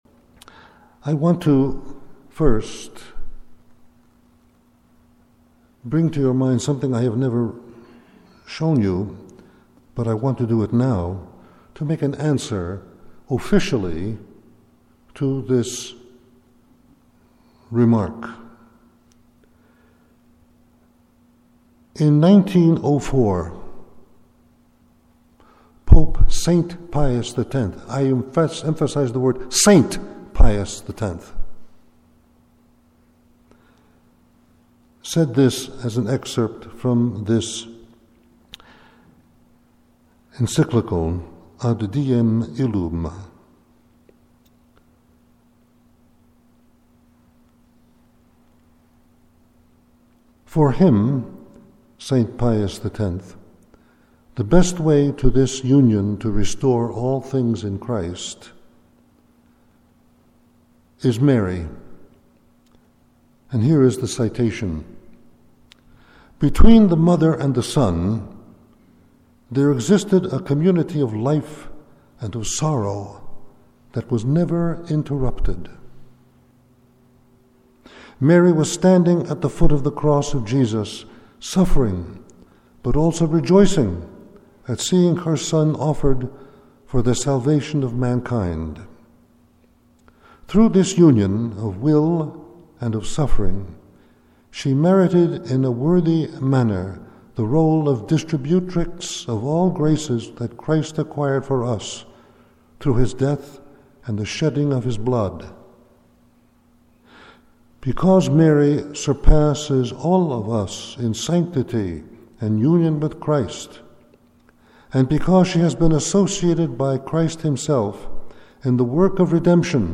If you missed it in our last newsletter, this sermon given to our community several years ago is a wonderful summary of the doctrine.